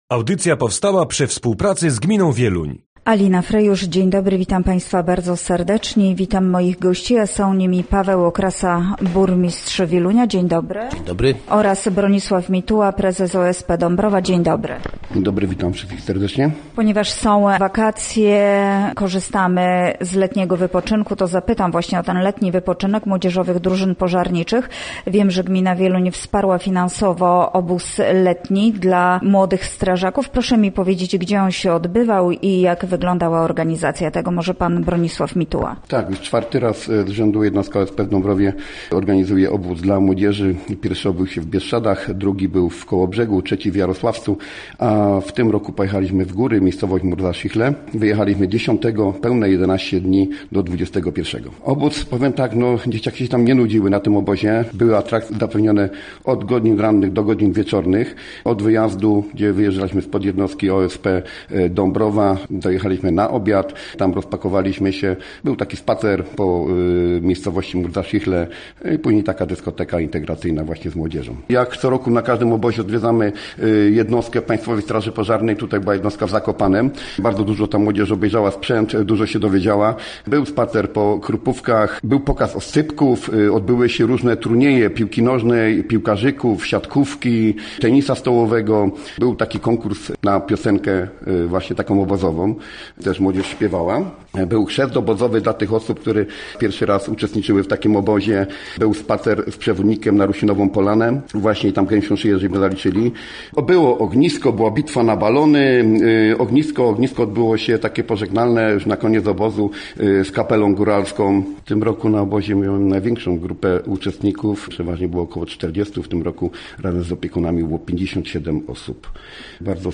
Gośćmi Radia ZW byli Paweł Okrasa, burmistrz Wielunia